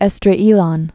(ĕsdrā-ēlŏn, -drə-, ĕz-), Plain of